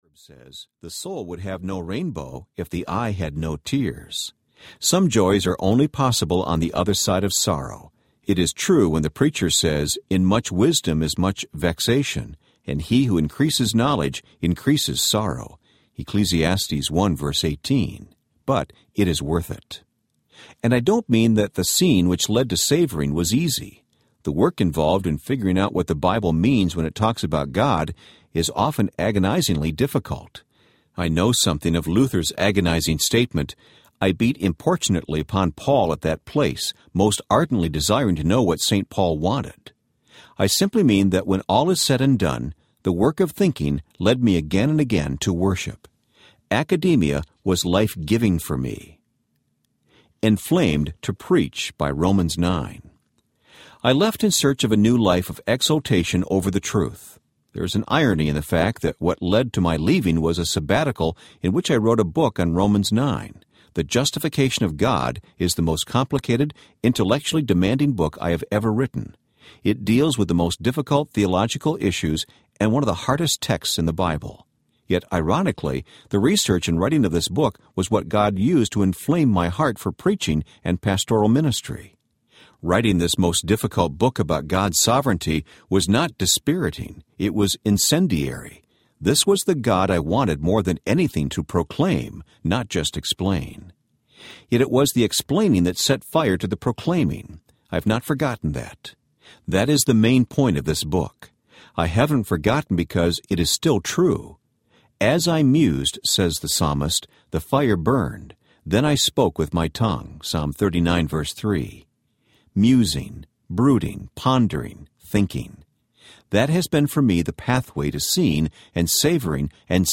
Think Audiobook
Narrator